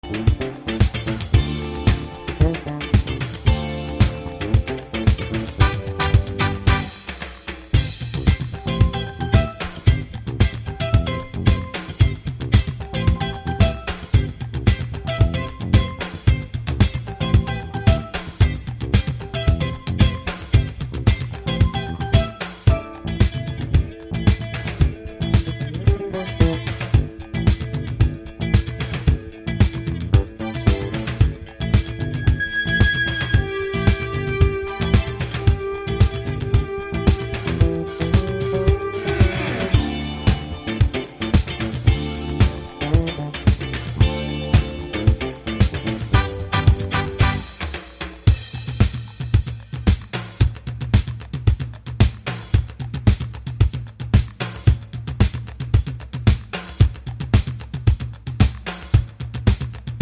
no Backing Vocals Pop (1980s) 2:36 Buy £1.50